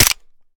weap_mike_fire_plr_mech_last_01.ogg